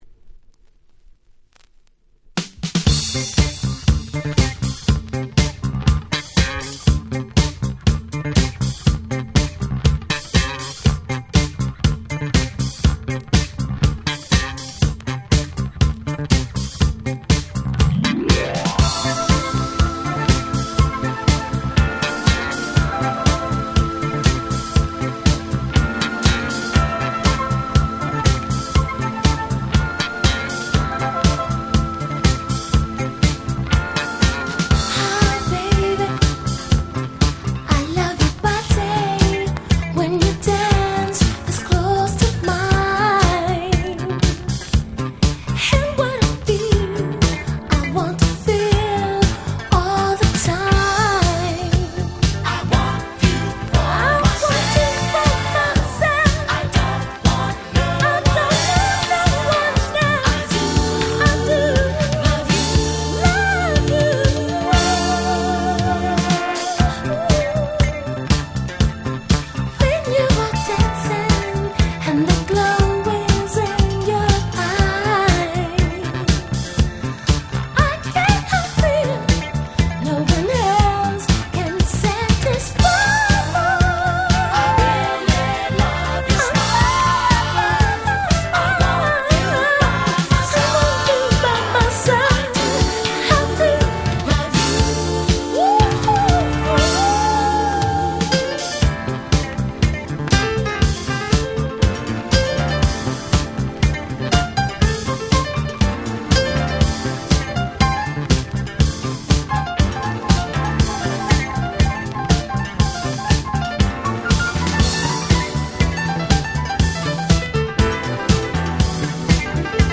79年のJazz Funk超名曲